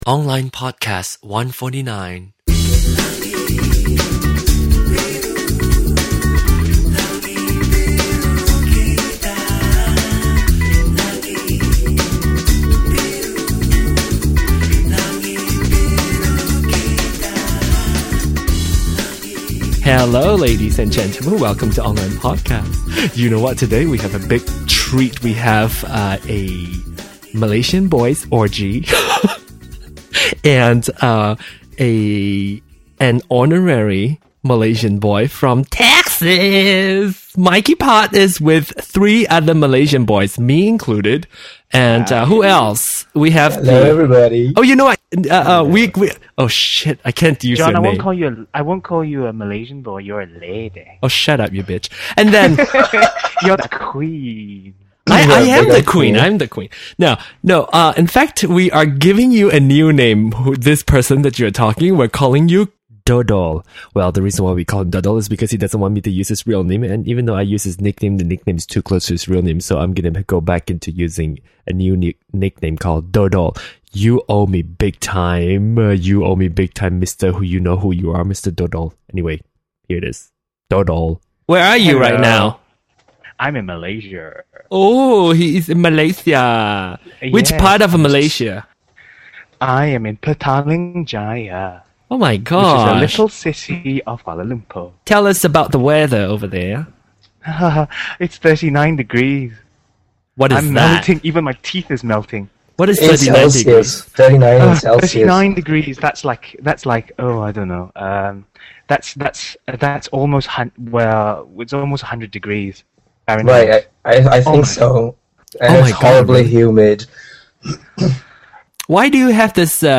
Show #149 | Duration: 1:02:20 | 28.6 MB | Mono |
It is a genuine discussion between four of us. Three Asians. One Caucasian. We discussed about stereotype, derogatory terms, culture, and being gay.